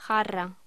Locución: Jarra
voz